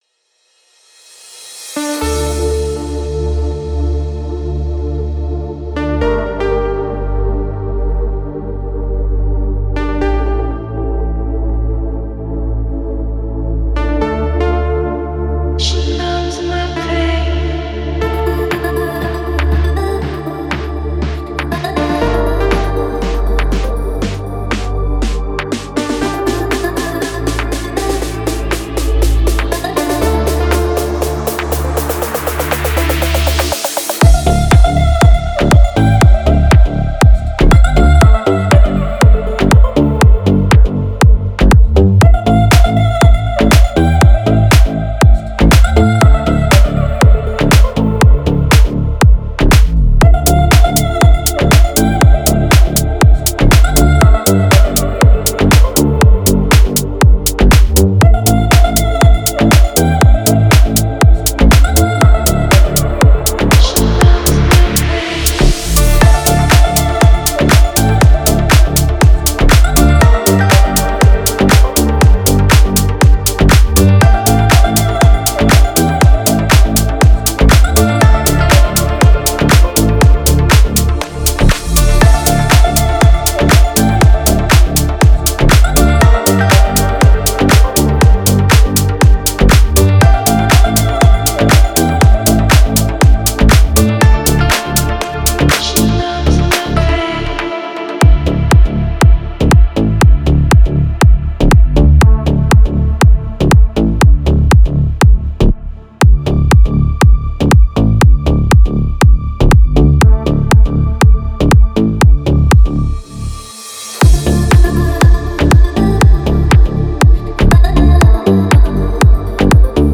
Жанр: Dance | Год: 2026